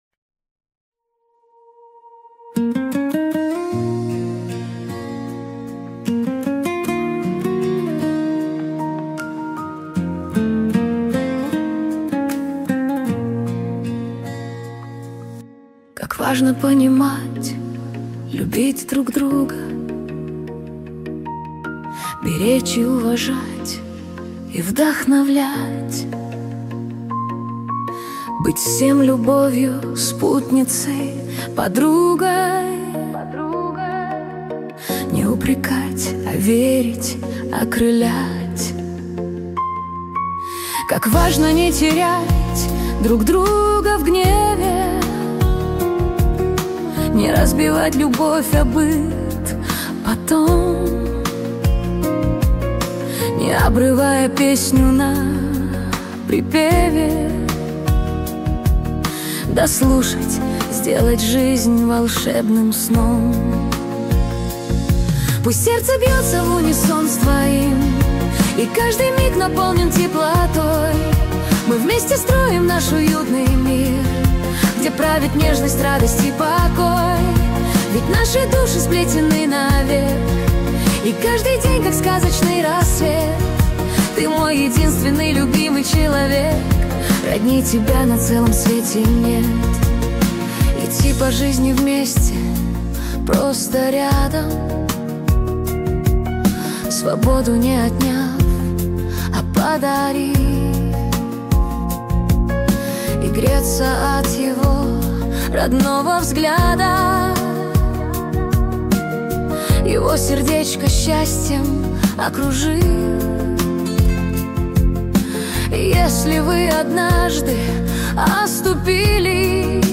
13 декабрь 2025 Русская AI музыка 77 прослушиваний